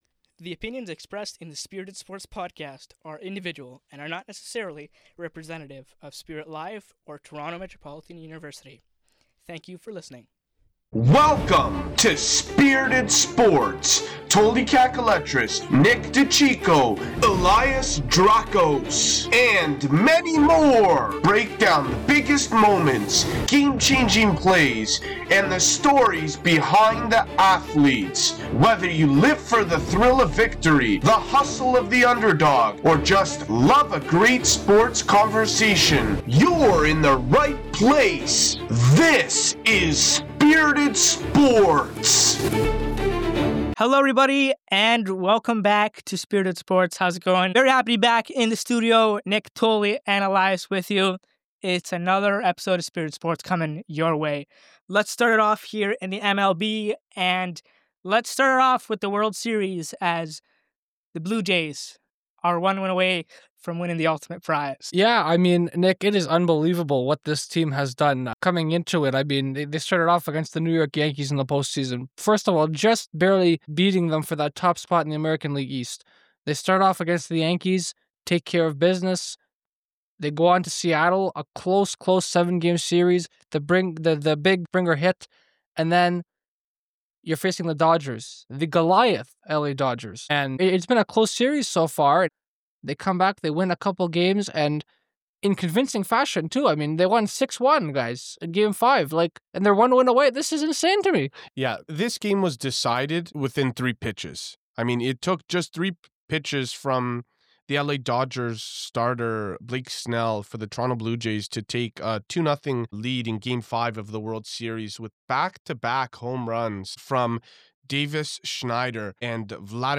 We also explore the latest NHL updates, including the impressive start of the Montreal Canadiens and the career milestones of legends like Sidney Crosby. Tune in for an engaging discussion of the sports world!